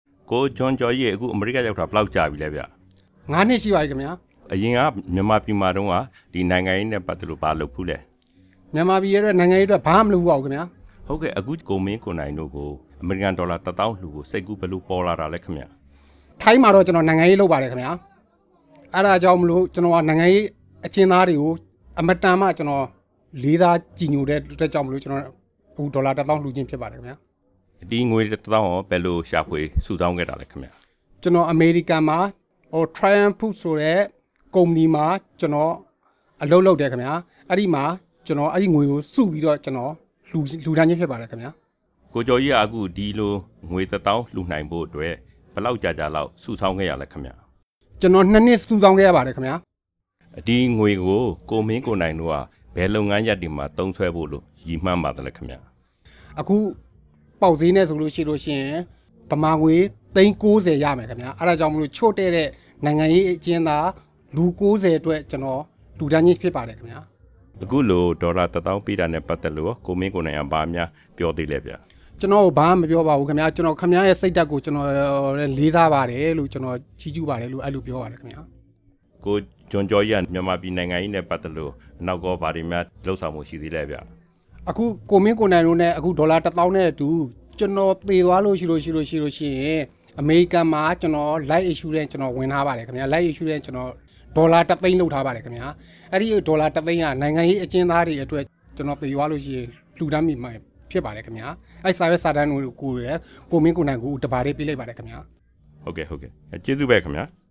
တွေ့ဆုံမေးမြန်းချက်